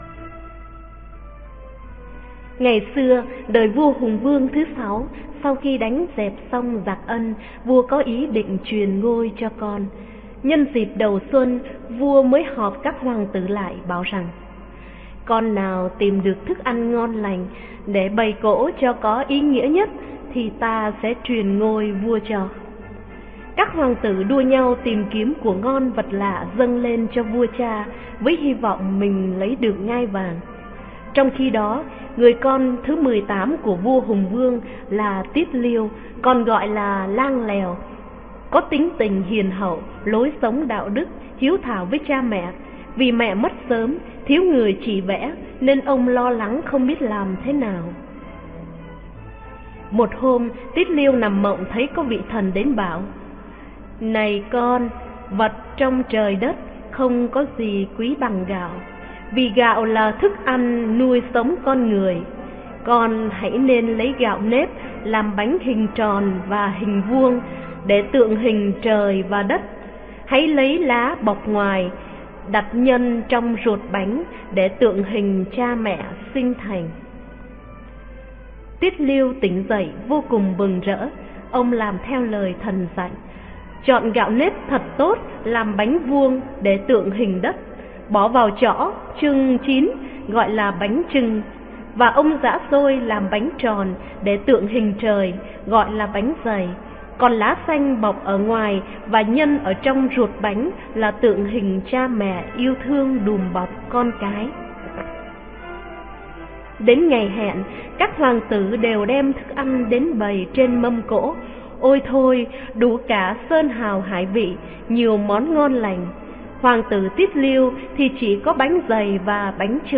Sách nói | Bánh Chưng - Bánh Dày